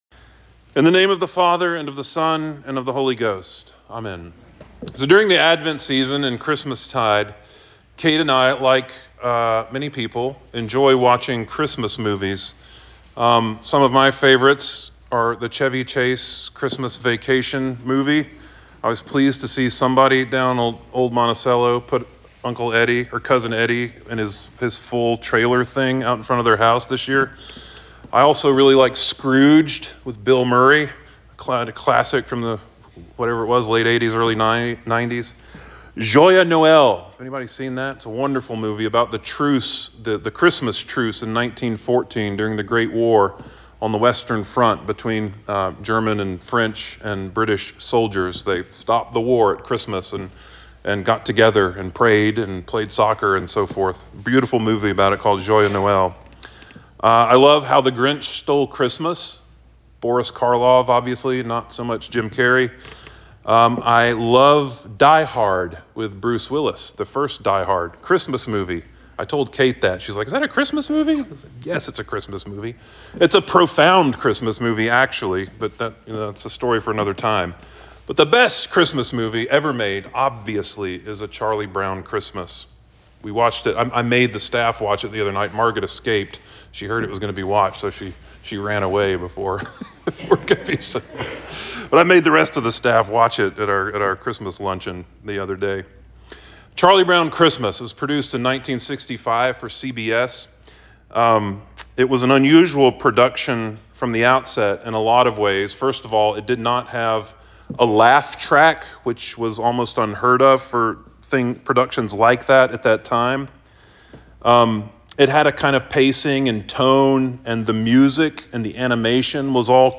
Sermon for Christmas Eve 2024